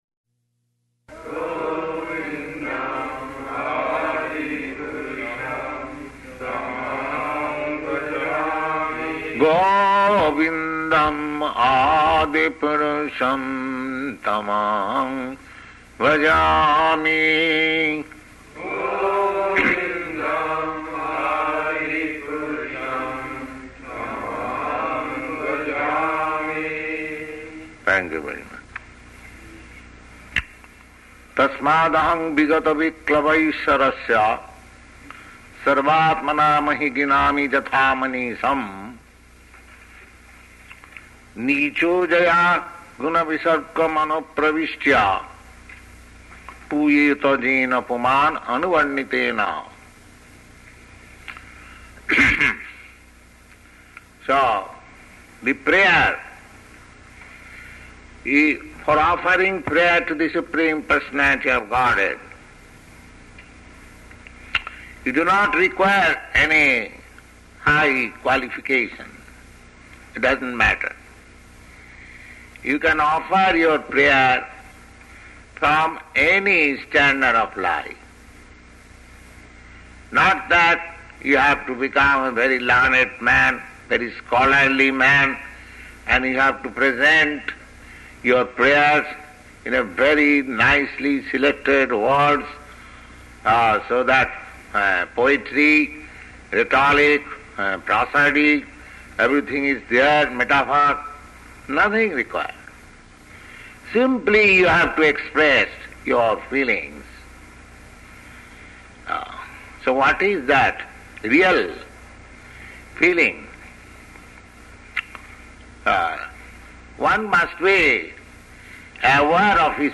Location: Montreal